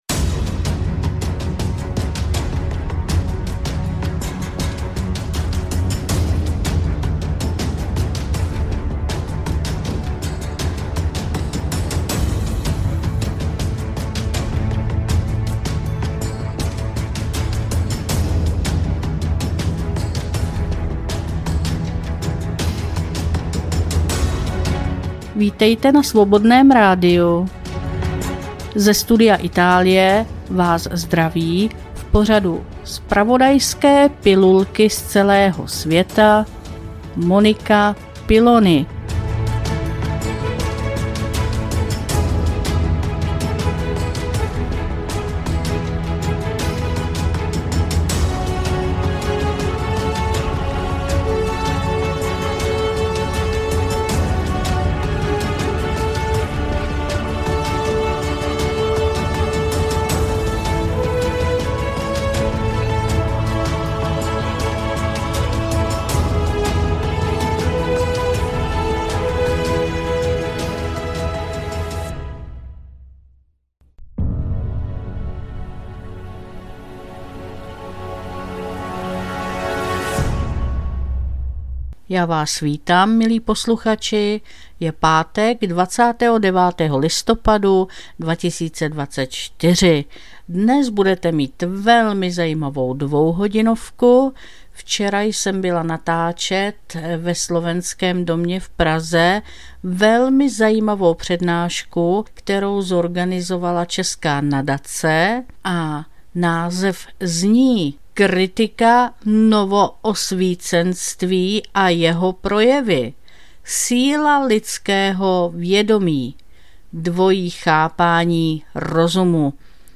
Studio Itálie - Natáčení ze semináře České nadace